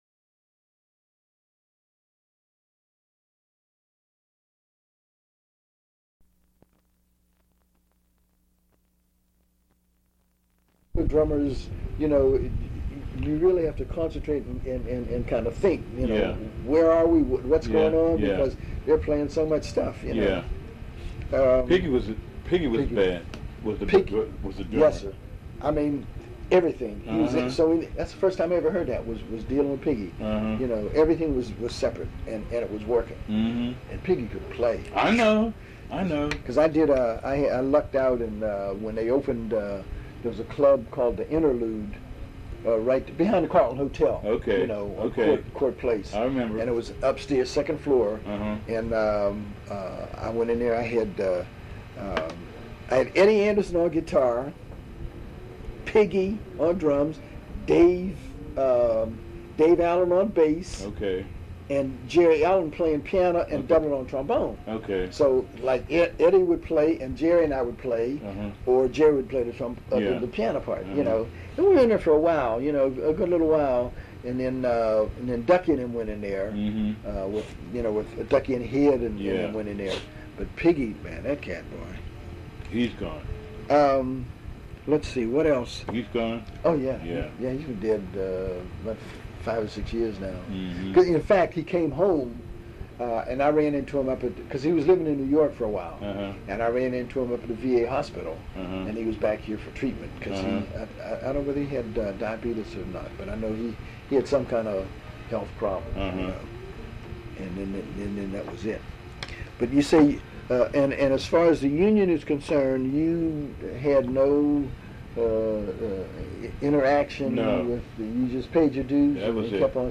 Beginning music